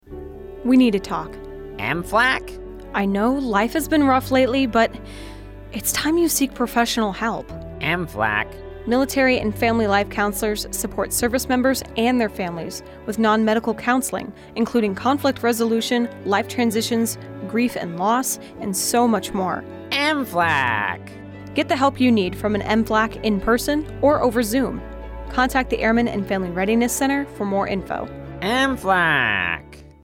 AFN Spangdahlem radio spot for Military and Family Life Counselors. Spot highlights services offered by MFLCs and contact information.